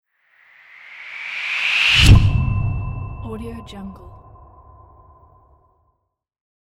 دانلود افکت صدای حماسی نهایی
افکت صدای حماسی نهایی یک گزینه عالی برای هر پروژه ای است که به انتقال و حرکت و جنبه های دیگر مانند،حماسه و افکت سینمایی نیاز دارد.
Sample rate 16-Bit Stereo, 44.1 kHz
Looped No